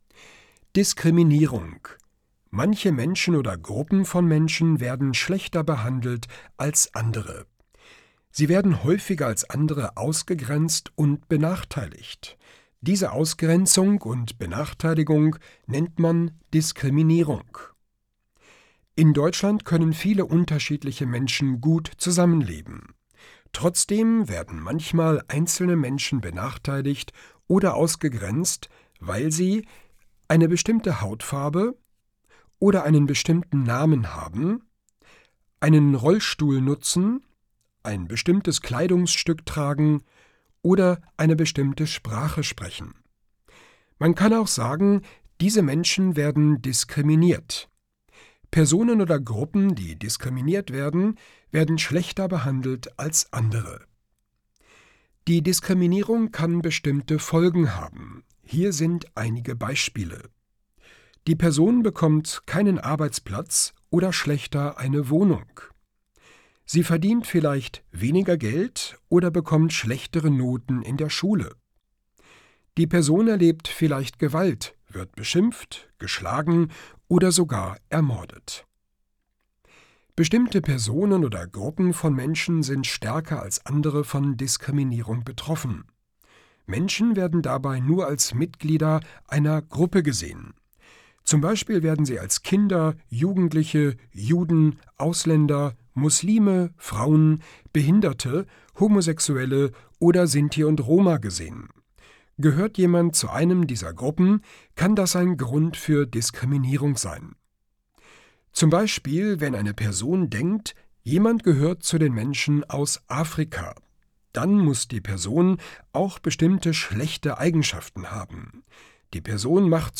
Studio Hannover
In einfacher Sprache